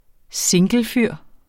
Udtale [ -ˌfyɐ̯ˀ ]